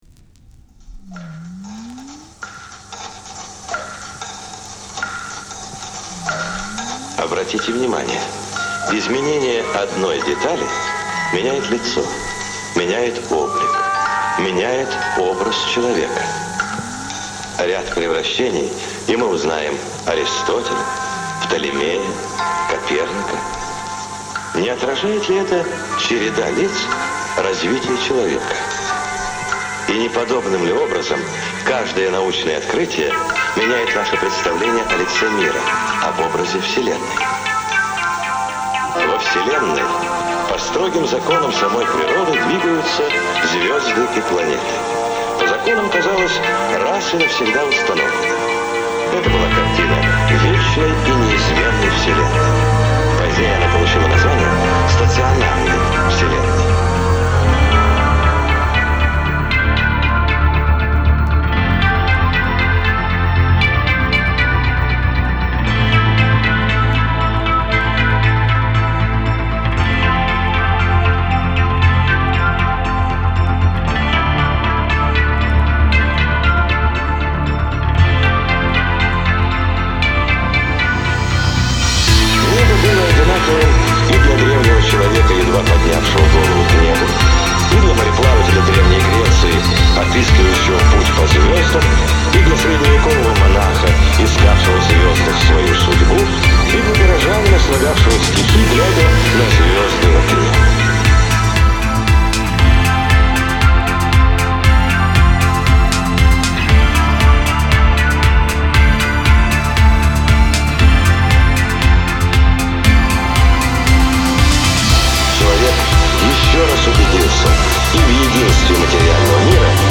Жанр: Synthwave, Retrowave, Spacewave, Dreamwave, Electronic